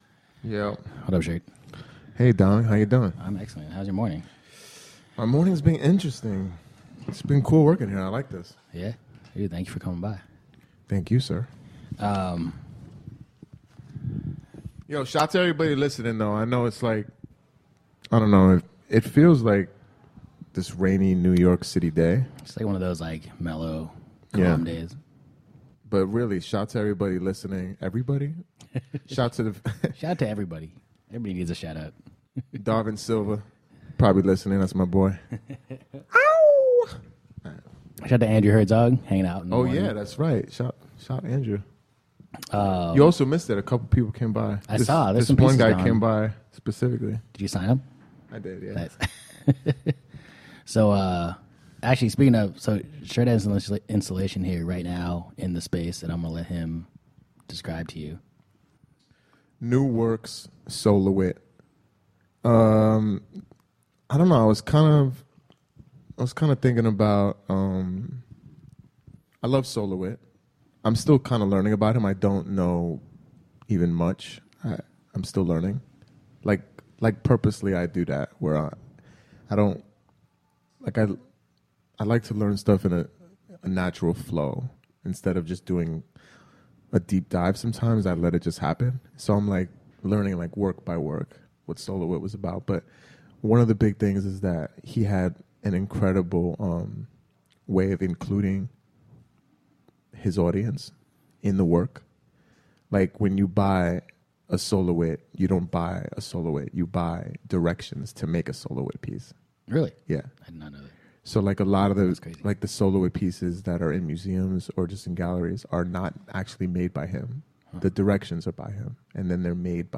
For season one, Office Hours , we took over a storefront in Chinatown and interviewed over 50 artists, designers, chefs, architects, entrepreneurs, and one politician, all to find out how they managed to make money doing what they love.
All of the interviews were recorded live.